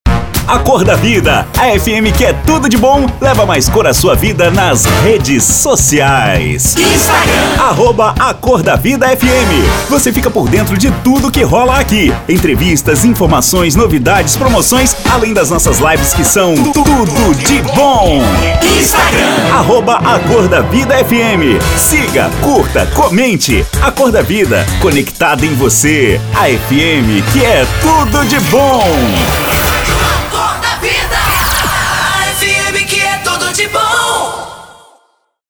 Impacto
Animada